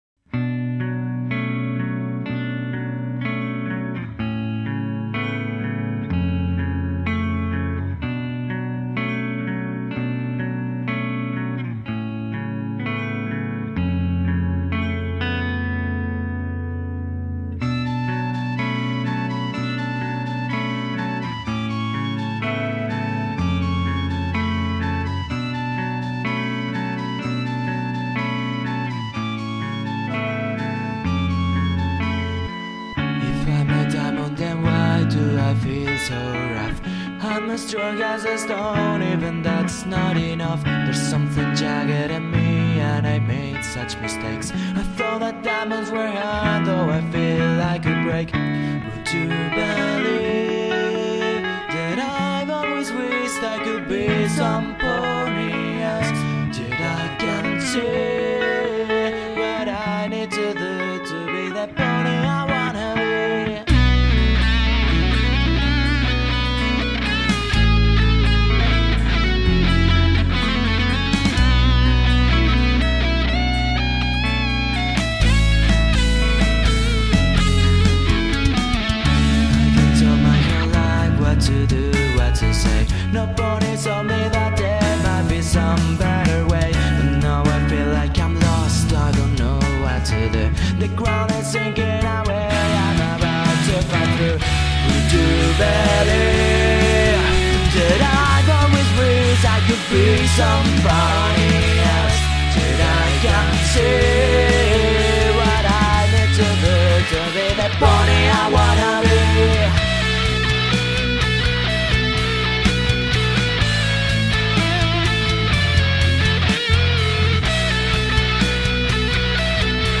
A little metal cover of this great song from the show.